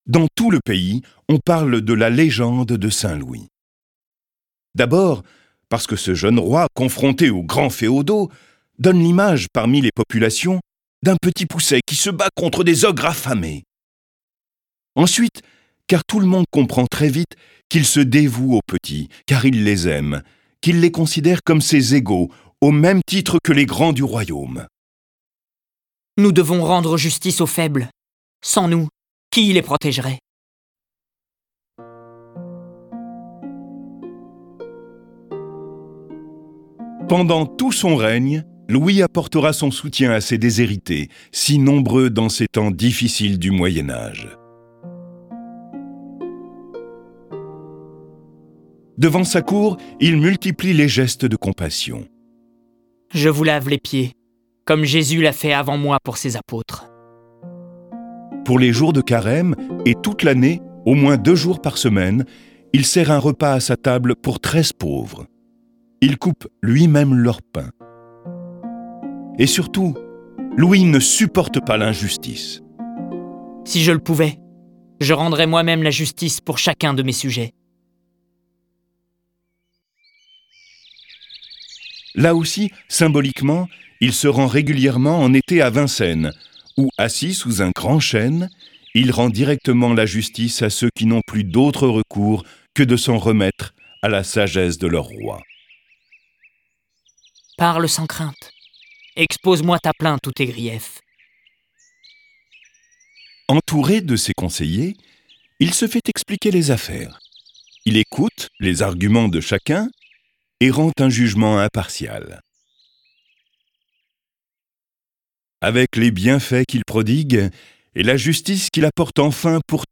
Diffusion distribution ebook et livre audio - Catalogue livres numériques
Cette version sonore de ce récit est animée par six voix et accompagnée de plus de trente morceaux de musique classique.